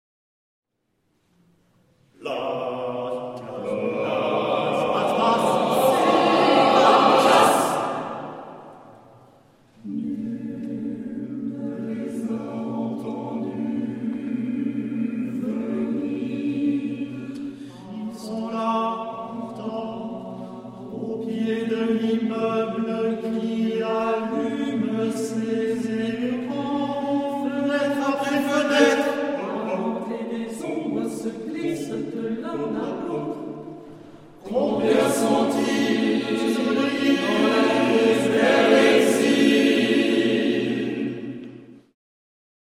pour 12 voix mixtes a capella
l’enregistrement est une trace du concert de création